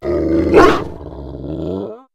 sovereignx/sound/direct_sound_samples/cries/mabosstiff.aif at a502427a7f5cc8371a87a7db6bb6633e2ca69ecb